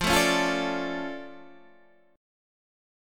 F Augmented 6th